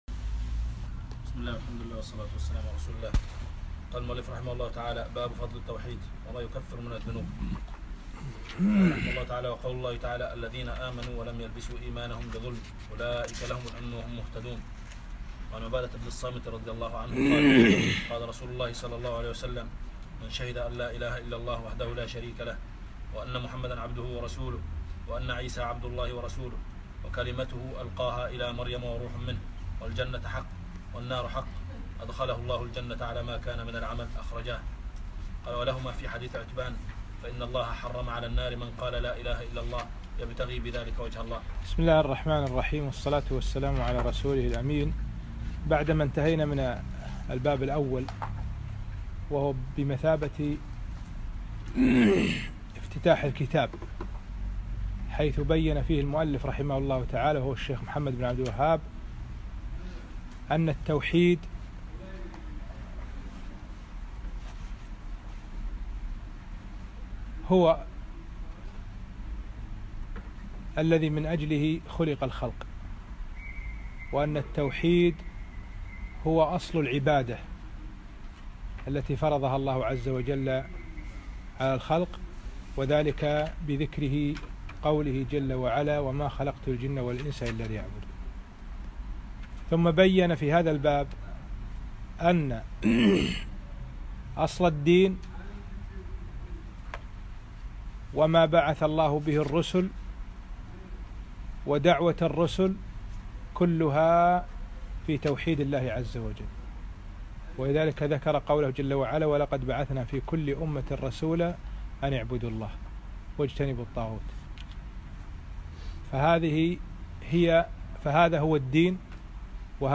الدرس الرابع